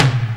44_04_tom.wav